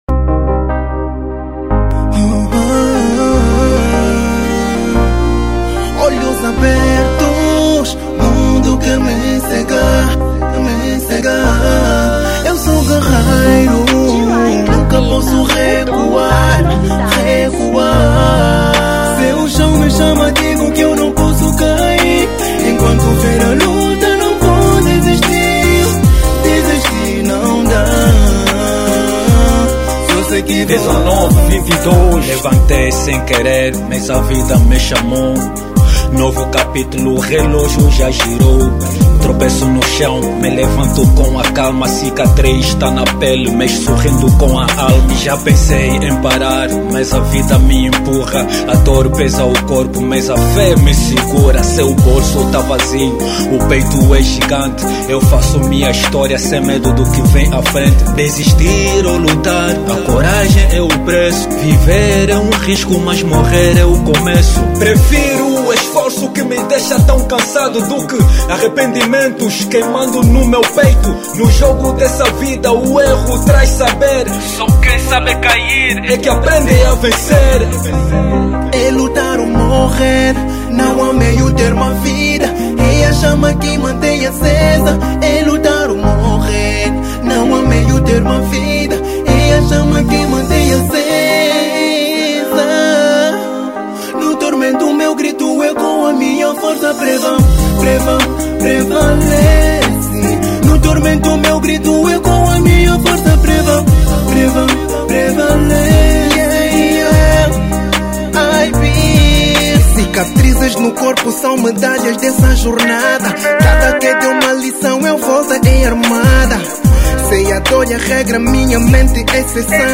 R&B 2025